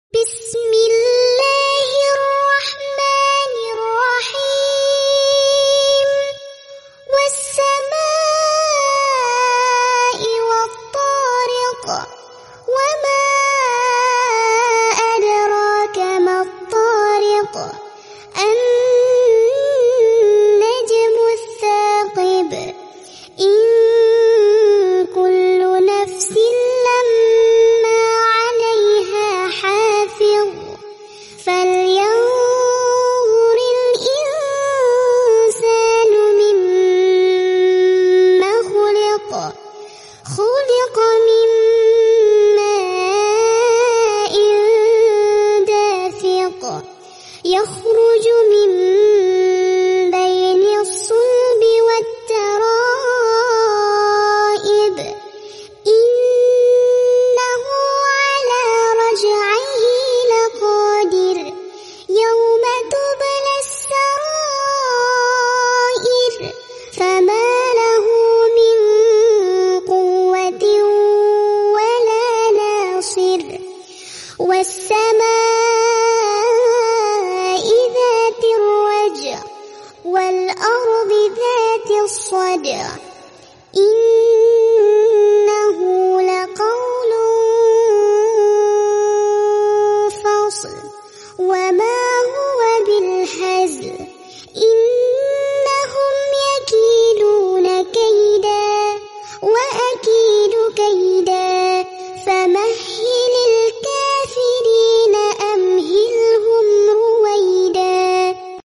Murottal Juz Amma Bacaan Anak Lipsync